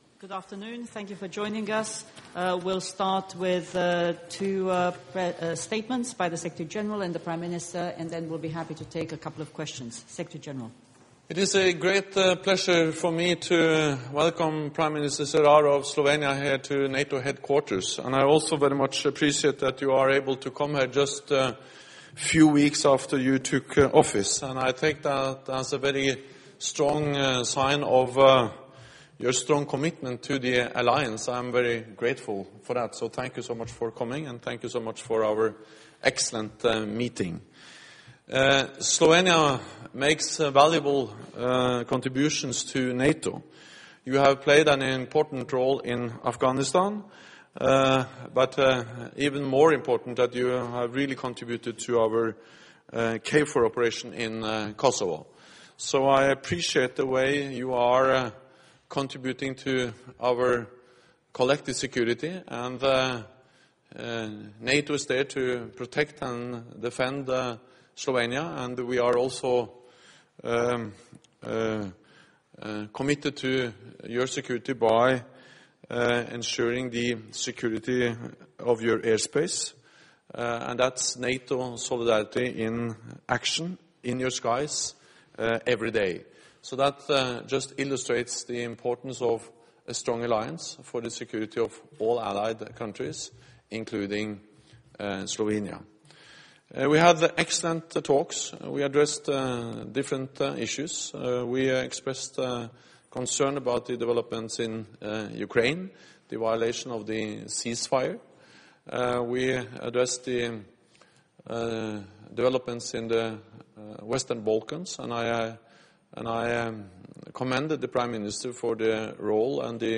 Joint press point with NATO Secretary General Jens Stoltenberg and the Prime Minister of Slovenia, Miro Cerar 11 Nov. 2014 | download mp3 FRENCH - Joint press point with NATO Secretary General Jens Stoltenberg and the Prime Minister of Slovenia, Miro Cerar 14 Nov. 2014 | download mp3